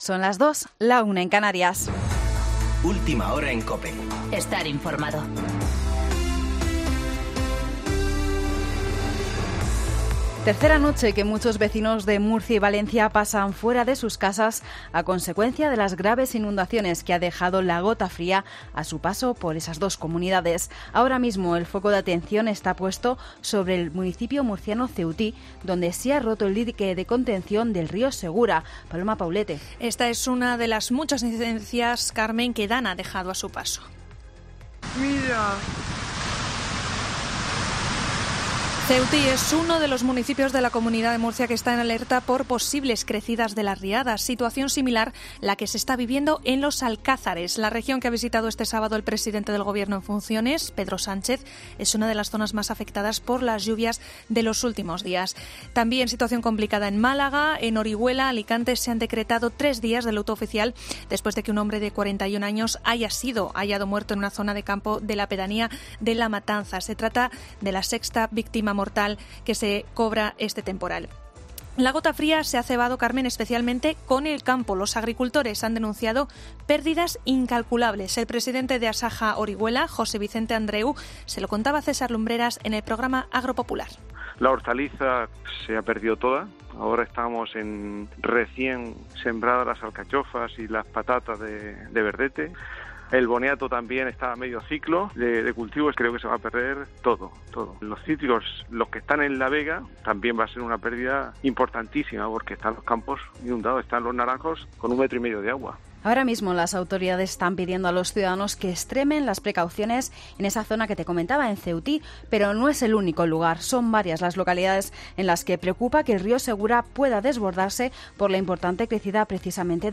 Boletín de noticias COPE del 15 de septiembre de 2019 a las 02.00 horas